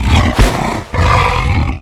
vampire_grasp.ogg